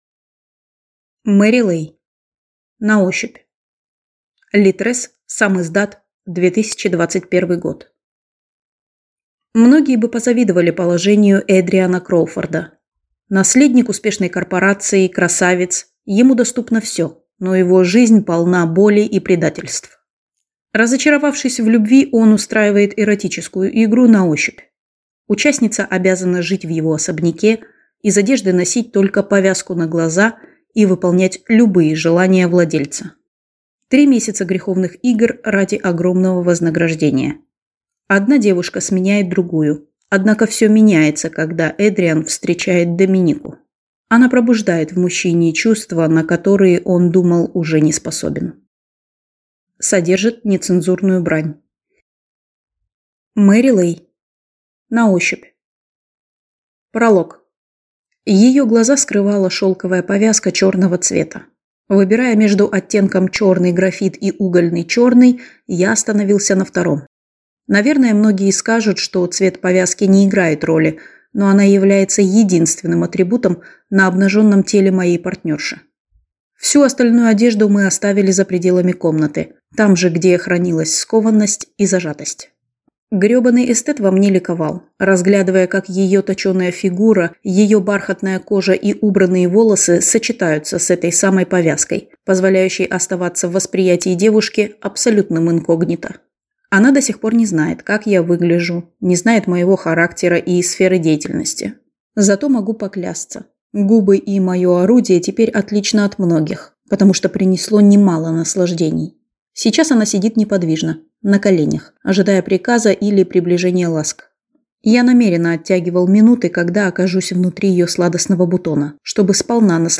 Аудиокнига На ощупь | Библиотека аудиокниг